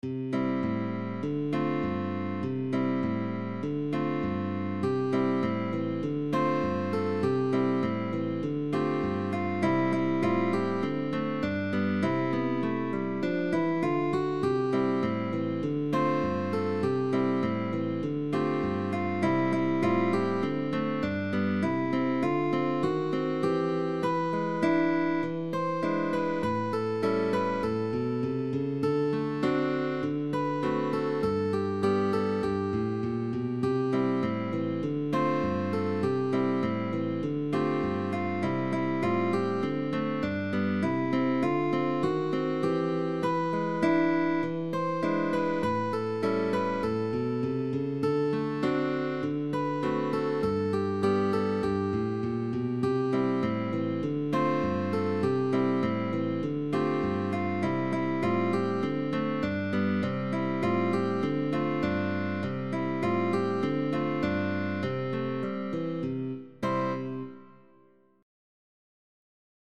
by guitar duo (Pupil & Teacher)
GUITAR DUO: PUPIL AND TEACHER